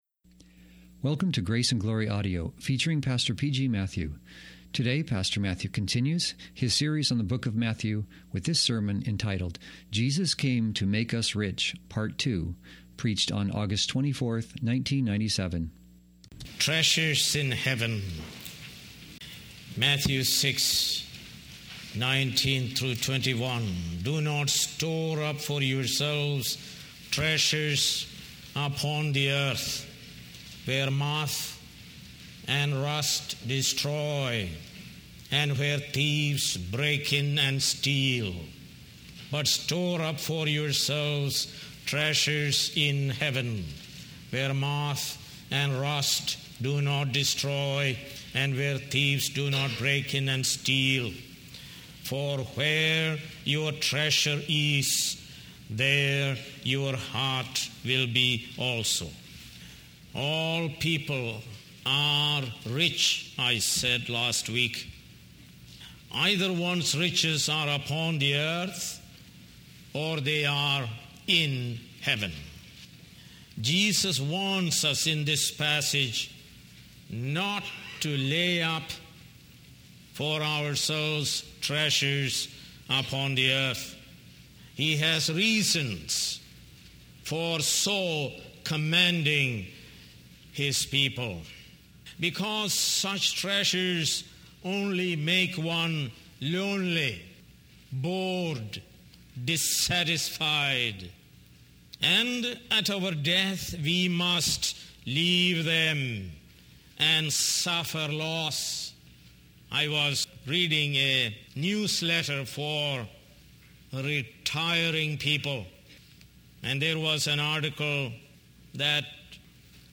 More Sermons From the book of Matthew